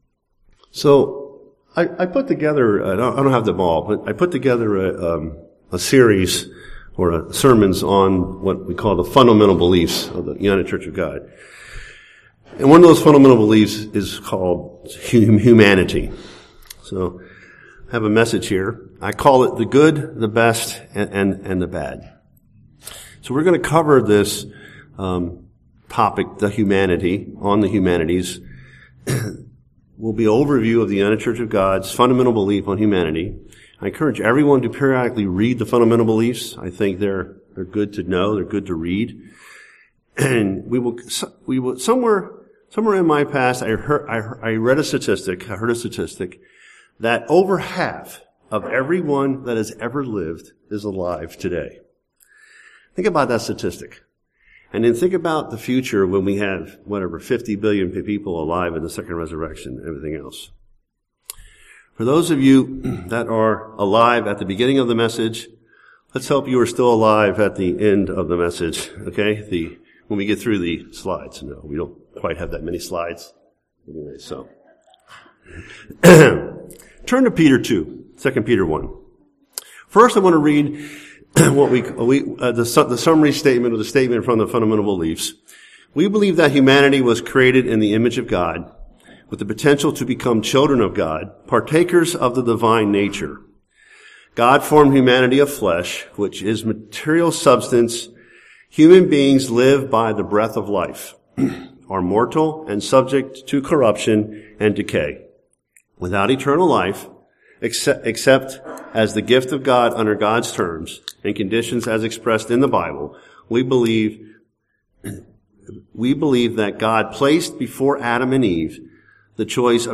Does man have an immortal soul? What are God's plans for humanity? Listen to this sermon to find out the answers to these questions as well as many others regarding
Given in San Jose, CA